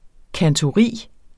Udtale [ kantoˈʁiˀ ]